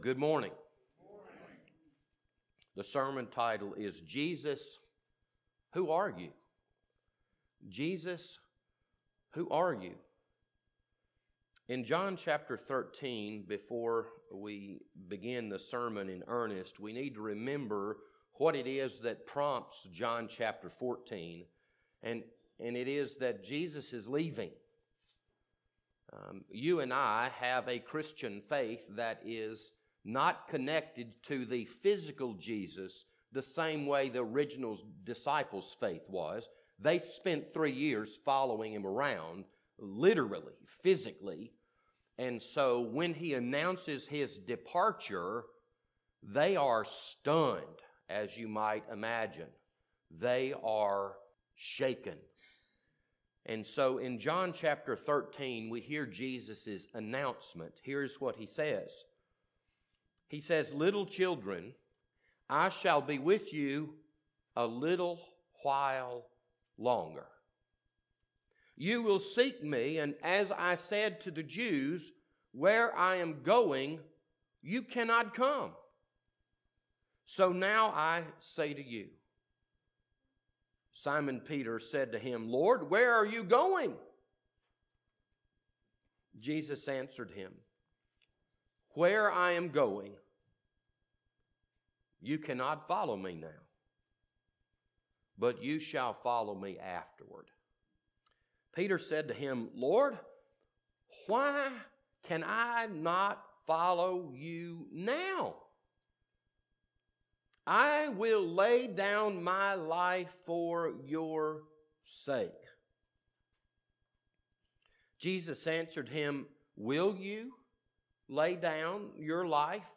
Joint Worship with Corinthian Missionary Baptist
This Sunday evening sermon was recorded on November 15th, 2020.